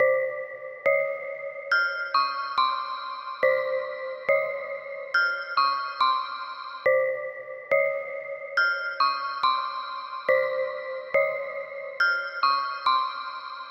标签： 女声 循环 低音 合成器 电子 舞蹈
声道立体声